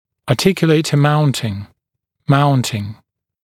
[ɑːˈtɪkjuleɪtə ‘mauntɪŋ], [‘mauntɪŋ]